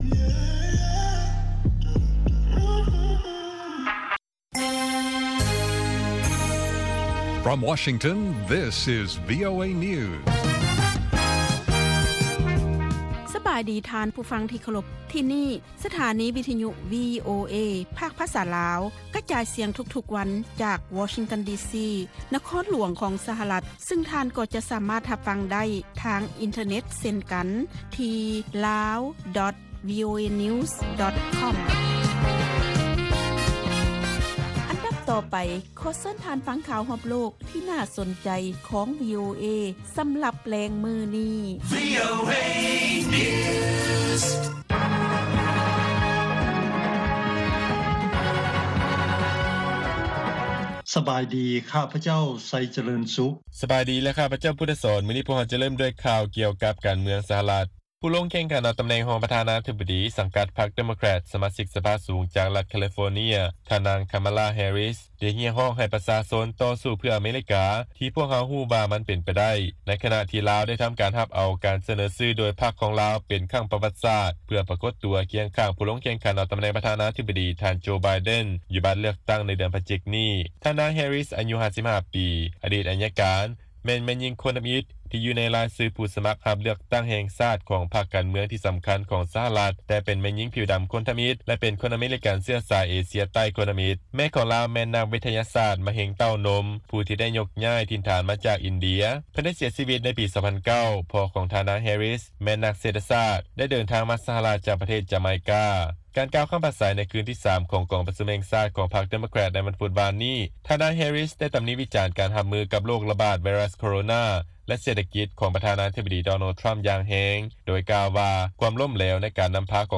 ລາຍການກະຈາຍສຽງຂອງວີໂອເອ ລາວ
ວີໂອເອພາກພາສາລາວ ກະຈາຍສຽງທຸກໆວັນ. ຫົວຂໍ້ຂ່າວສໍາຄັນໃນມື້ນີ້ມີ: 1) ສະຫະລັດ ຍົກເລີກ ຂໍ້ຕົກລົງດ້ານກົດໝາຍ ແລະການເງິນ 3 ສະບັບ ກັບຮົງກົງ. 2) ທ່ານນາງ ແຮຣິສ ຮຽກຮ້ອງໃຫ້ຕໍ່ສູ້ເພື່ອ ອາເມຣິກາ ໃນຂະນະທີ່ທ່ານນາງ ຮັບເອົາການສະເໜີຊື່ ລົງແຂ່ງຂັນ ເປັນຮອງປະທານາທະບໍດີ. 3) ວີໂອເອ ໂອ້ລົມກັບ ສາສນາຈານ ດຣ.